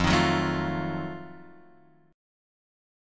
F7b9 chord